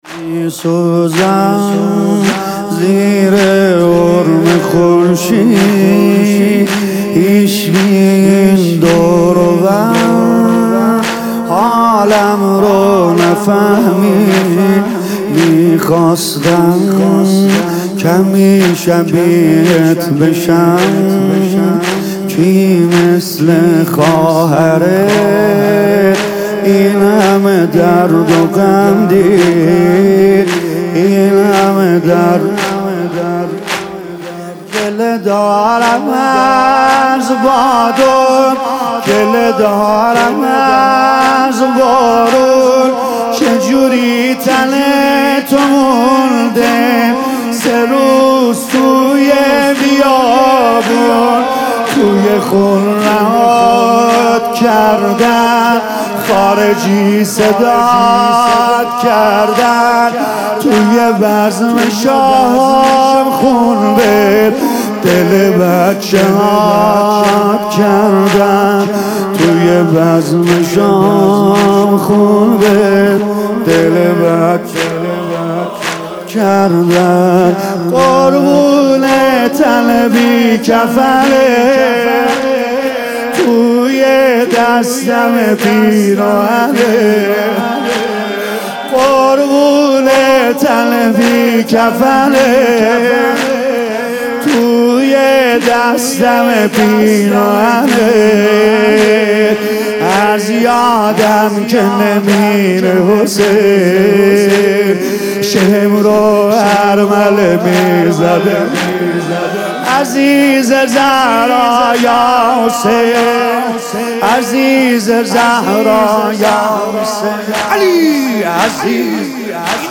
music-icon زمینه: نه فقط مؤذن جامعه که تو پیش‌نماز جماعتی